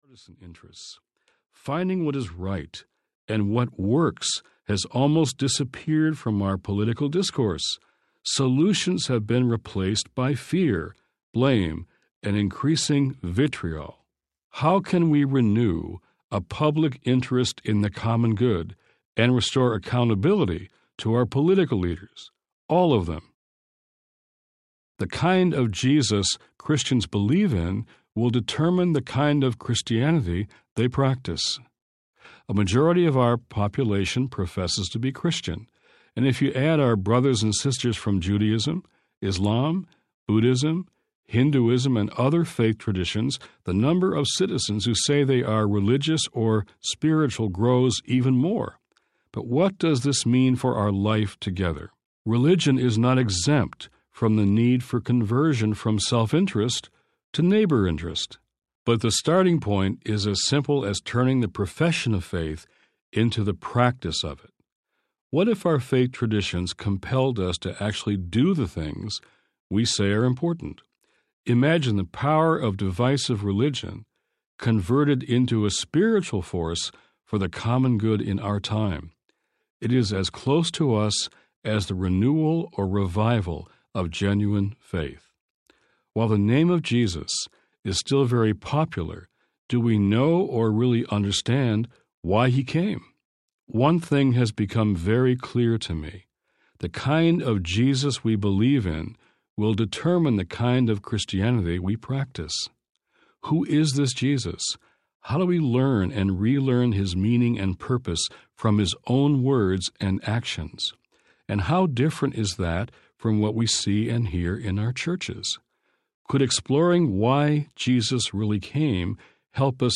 On God’s Side Audiobook
Narrator